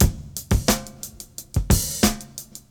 • 88 Bpm Drum Loop Sample E Key.wav
Free drum loop sample - kick tuned to the E note. Loudest frequency: 2558Hz
88-bpm-drum-loop-sample-e-key-Ikp.wav